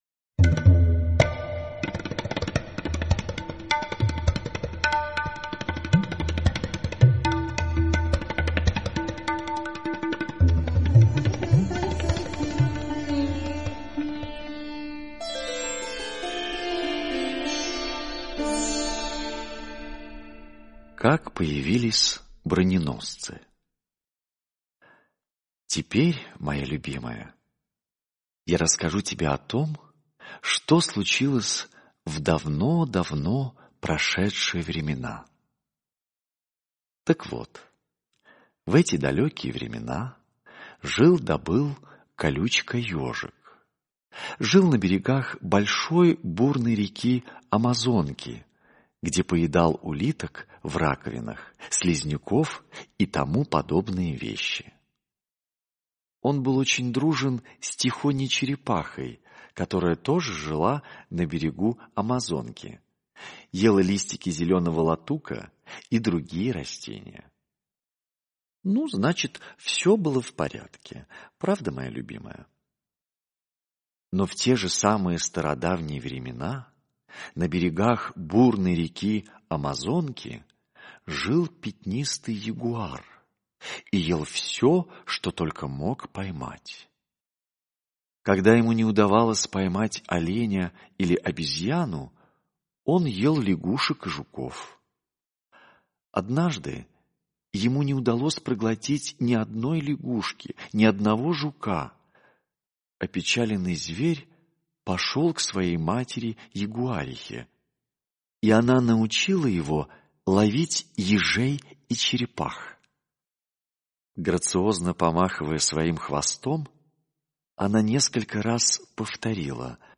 Как появились броненосцы - аудиосказка Киплинга Р. Сказка о том, как самка ягуара учила своего детеныша охотится на ежа и черепаху.